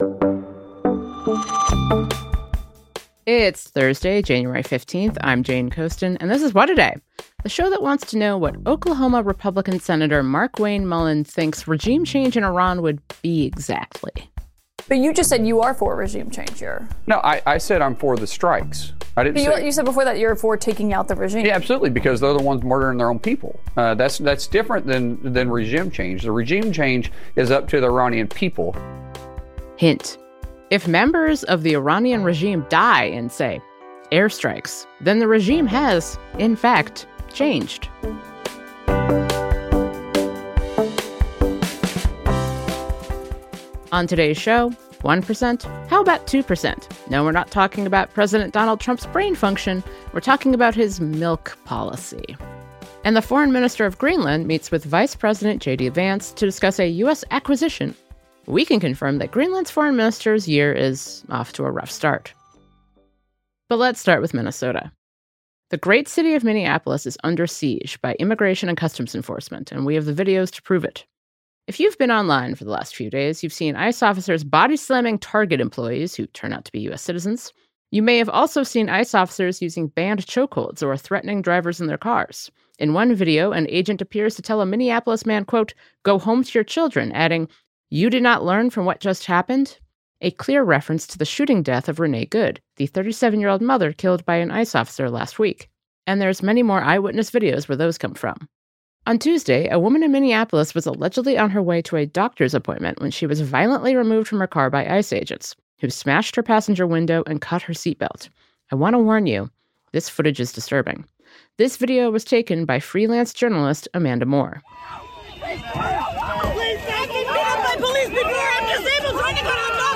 But what can state and local authorities do about it? To find out, we spoke to Minnesota Attorney General Keith Ellison.